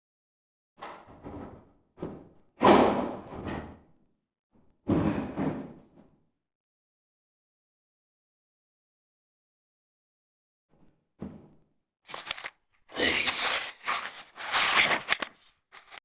EVP2 (Electronic Voice Phenomena) I have heard in eight years of paranormal investigating.
bath house evp noise red.WAV